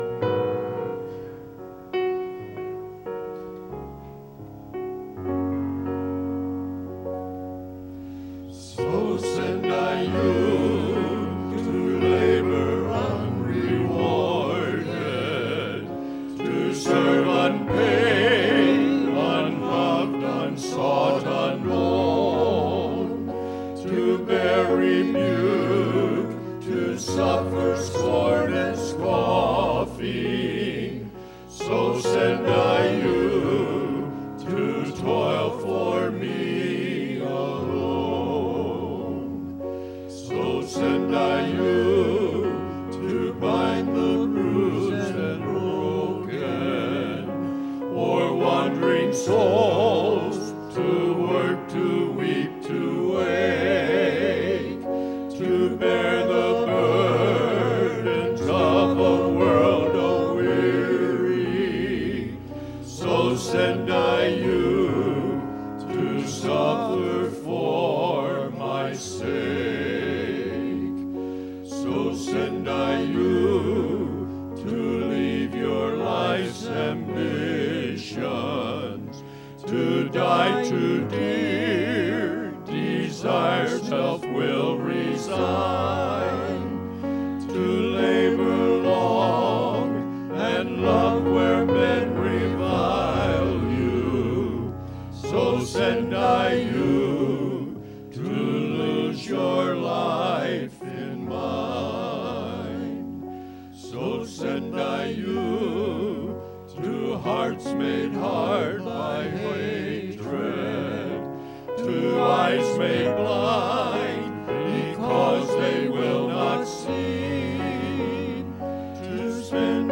“So Send I You” – Men’s Quartet – Faith Baptist